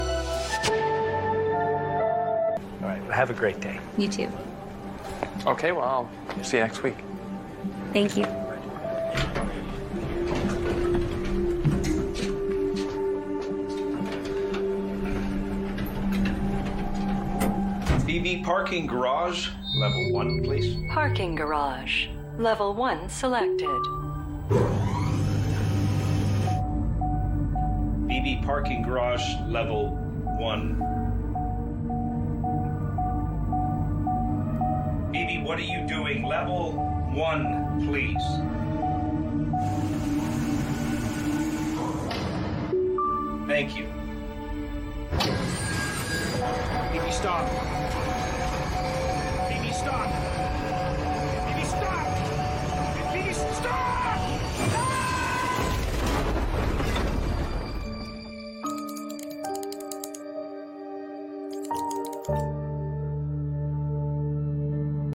elevator.ogg